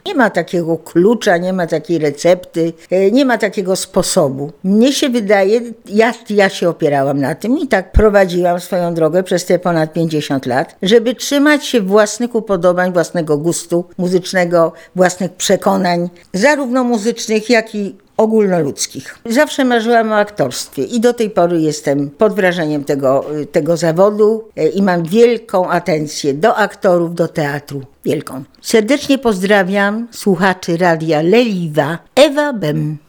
Jaki jest przepis bycia wciąż znanym na scenie muzycznej przez 50 lat, Ewa Bem odpowiada: